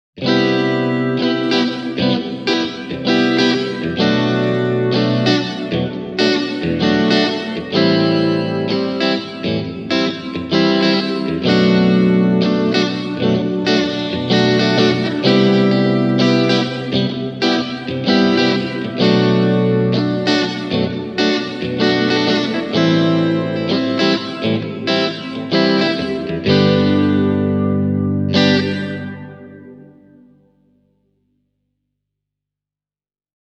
Supernova on soundiltaan hyvin laaja ja syvä kaiku, johon on lisätty sekä flangeri että pitch shifter -efekti:
digitech-hardwire-supernatural-e28093-supernova.mp3